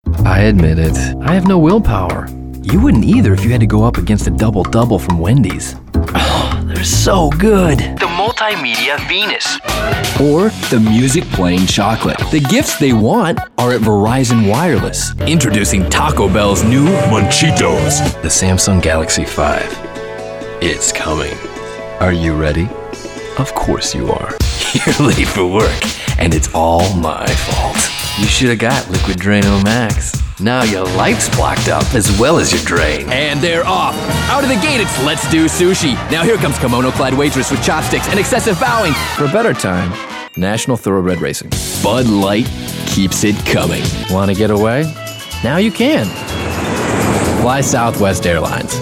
Voiceover : Commercial : Men
Commercial Demo